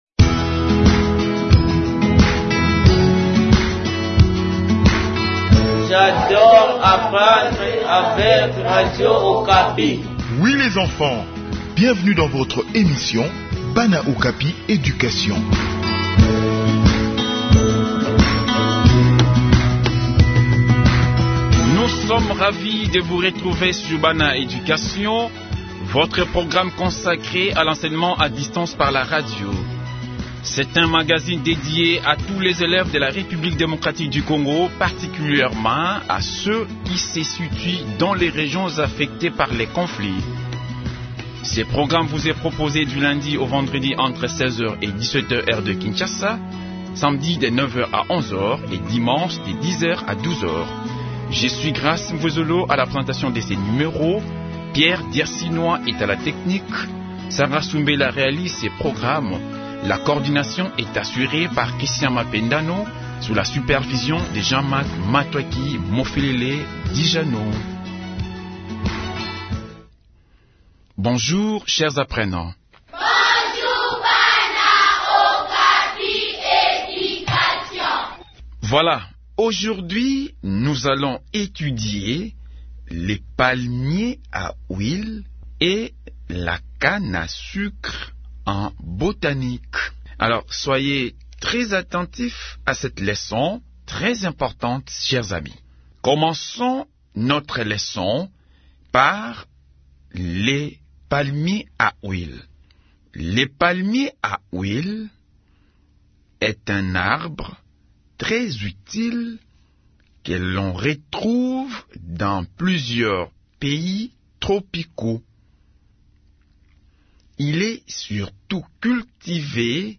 Enseignement à distance : leçon de botanique sur les palmiers à huile et la canne à sucre